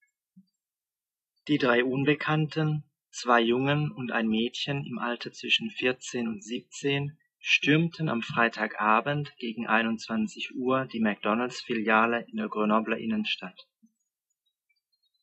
Aussprache: einen Text lesen